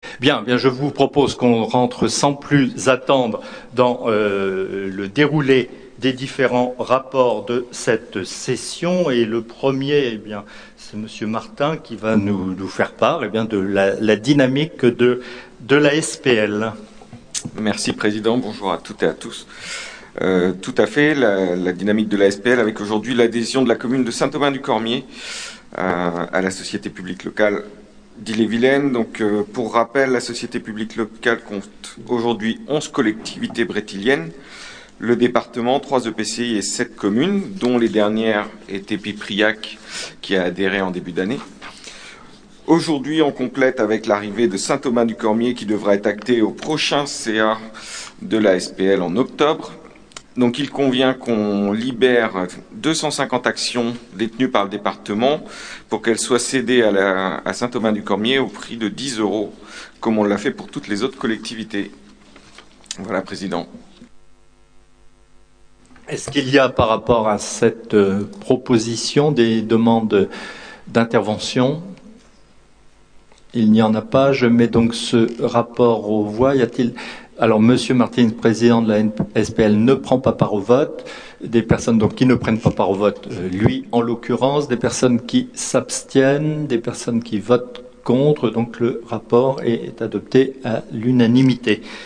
• Assemblée départementale du 29/09/22